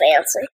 Vox (Fancy).wav